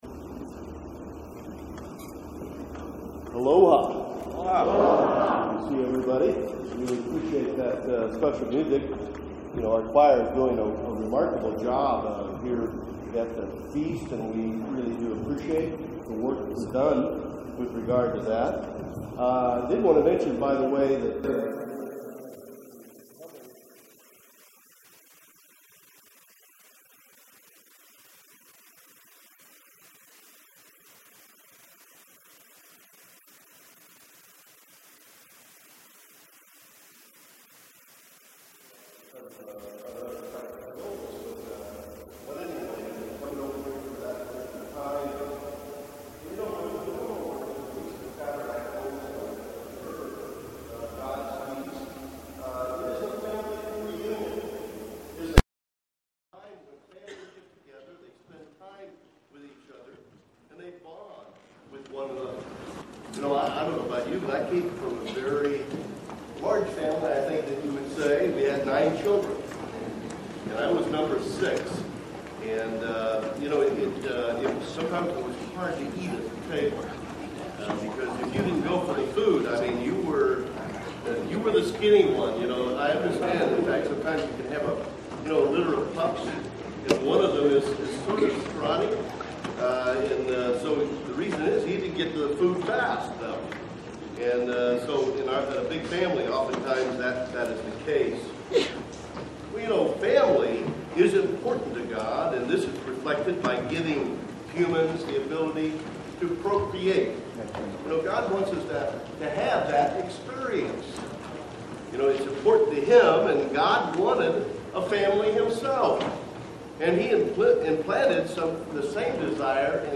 (Audio problems for the first three minutes.) Jerusalem is at the center of it all with God's plans for the future. Prophecy (Ezek. 40-48) focuses on the importance of it for the millennium and far beyond.
This sermon was given at the Maui, Hawaii 2015 Feast site.